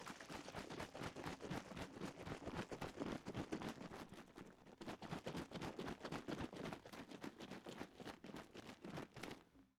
household
Shaking Mouthwash Container